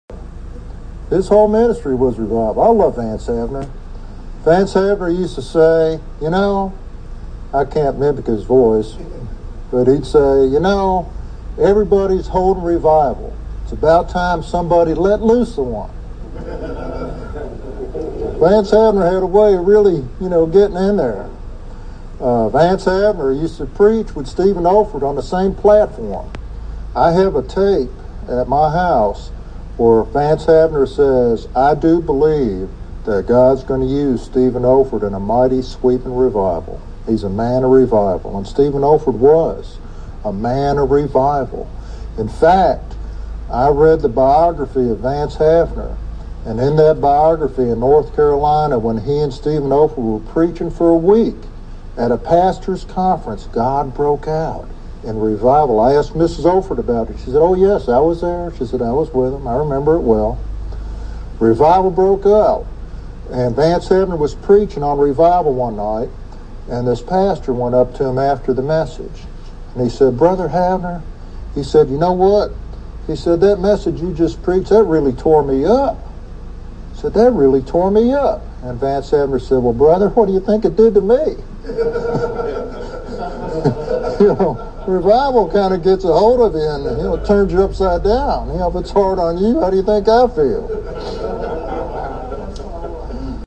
Sermon Outline
This sermon is primarily devotional, sharing testimonies and reflections on revival.